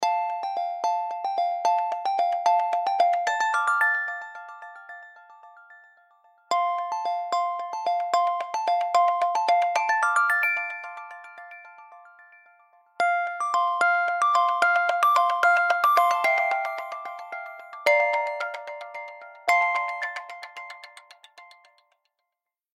Instrumentalmusik